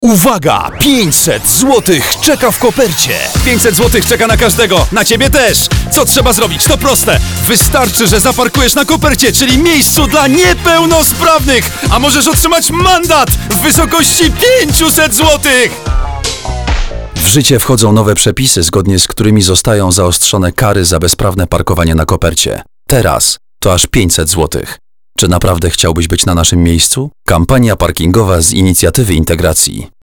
Spot radiowy Kampanii Parkingowej (.mp3 1,2 MB)
kampania_parkingowa_radio.mp3